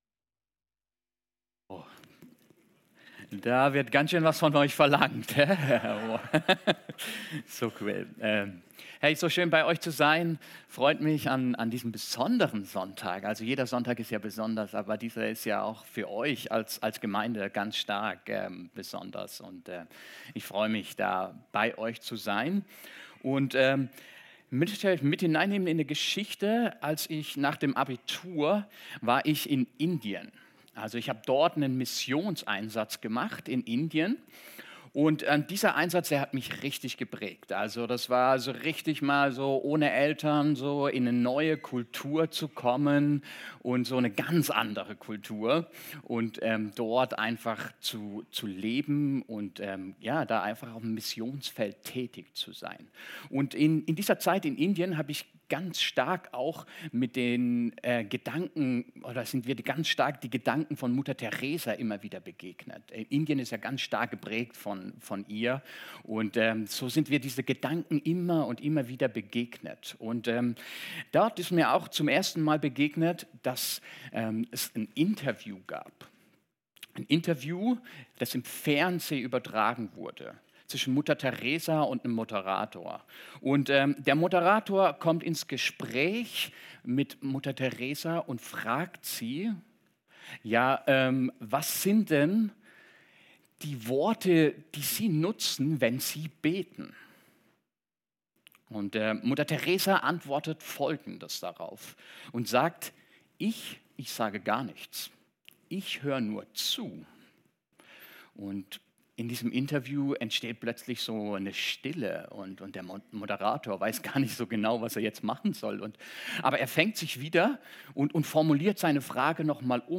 Predigt vom 01.03.2026 | Podcast der Stadtmission Alzey